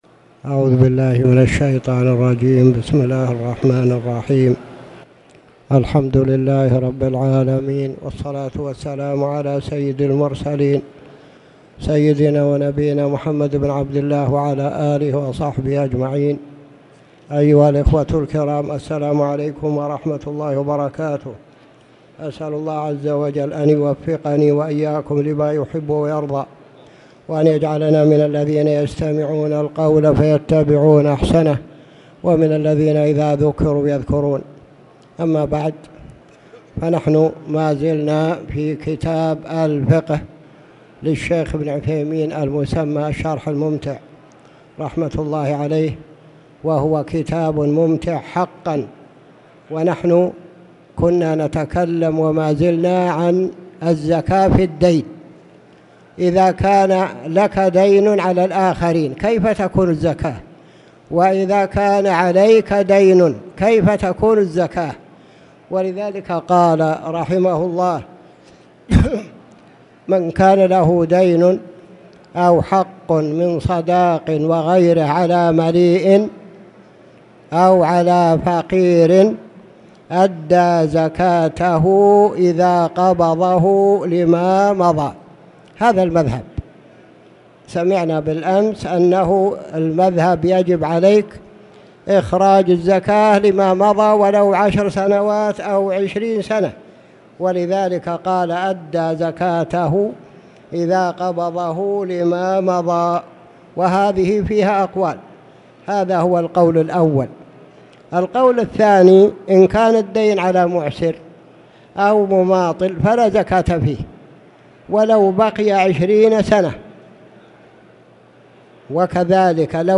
تاريخ النشر ٩ جمادى الآخرة ١٤٣٨ هـ المكان: المسجد الحرام الشيخ